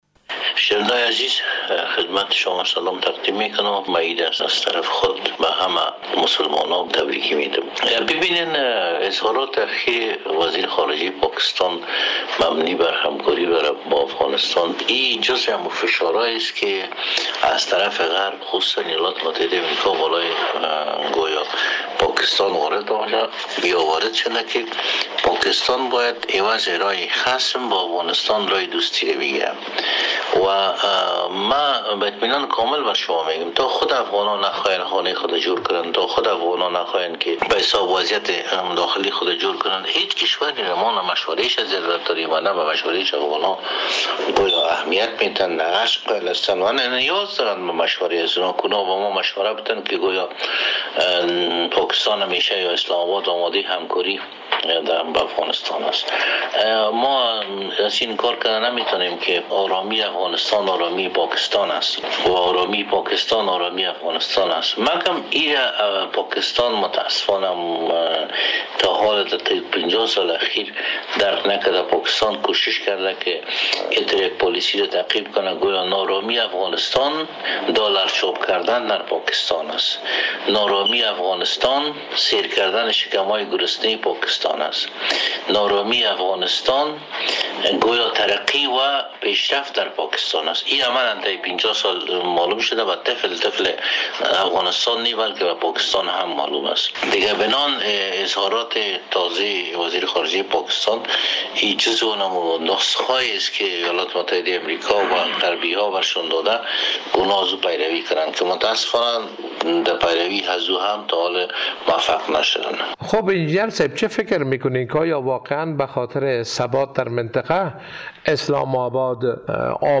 در گفت و گو با خبرنگار رادیو دری در کابل